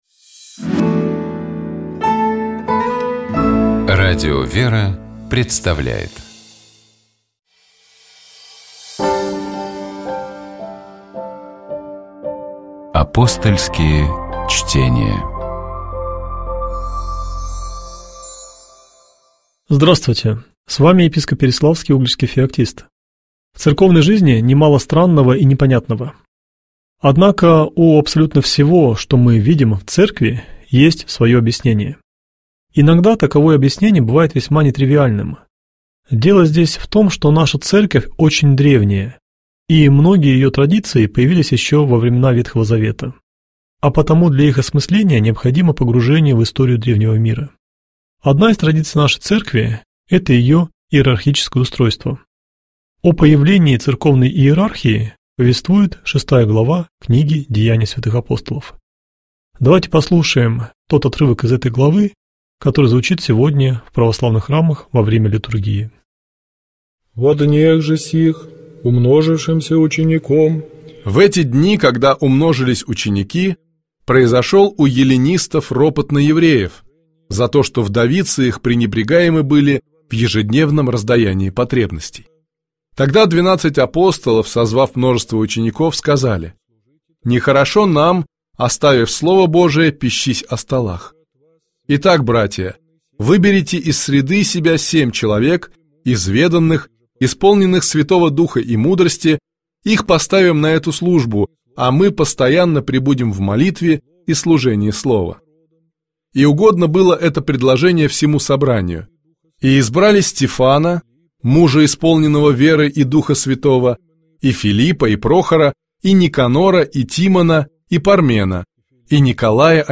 Комментирует епископ Переславский и Угличский Феоктист.